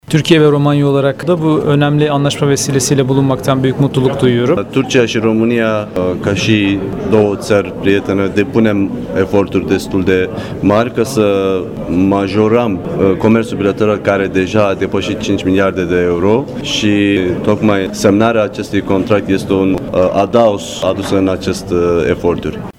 La ceremonia de semnare a contractului a participat și Osman Koray Ertaş, ambasadorul Turciei în România: